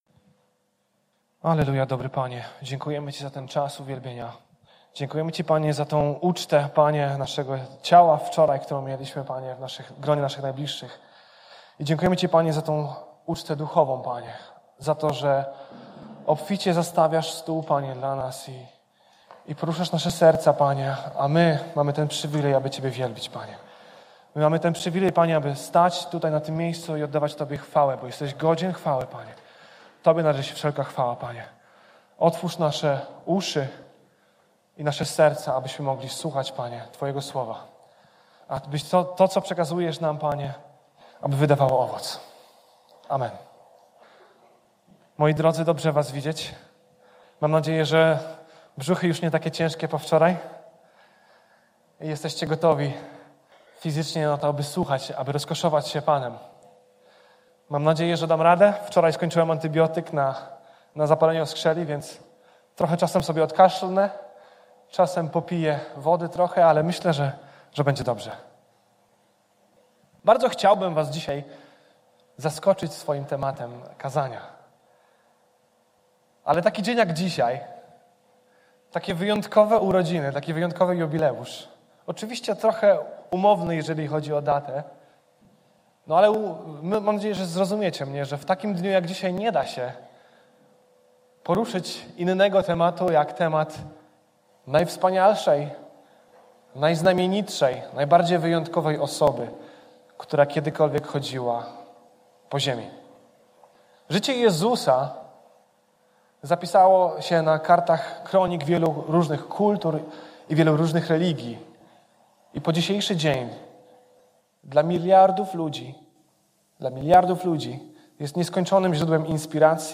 25.12.2024 „Nabożeństwo świąteczne”